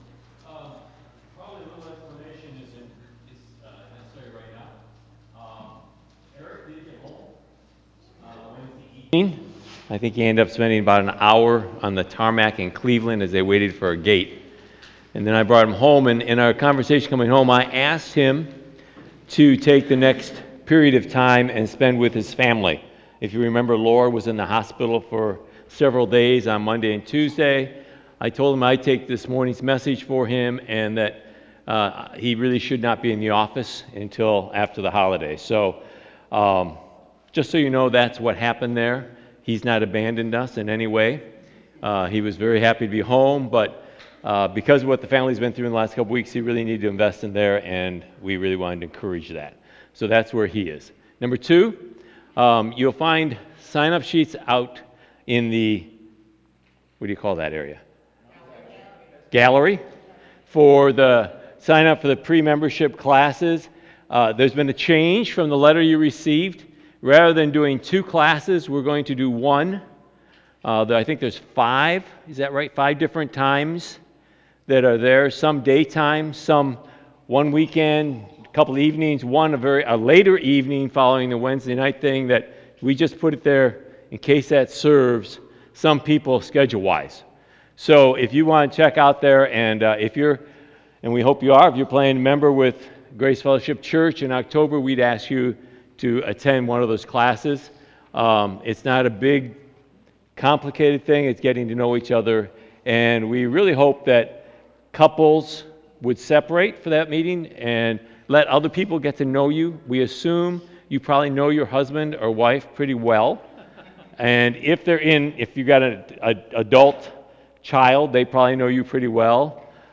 September 3 Sermon | A People For God